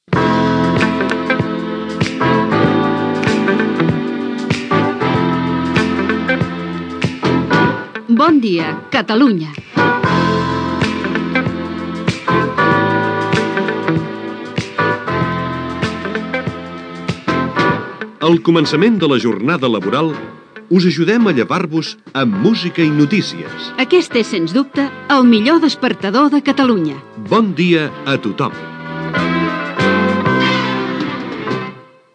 Careta del programa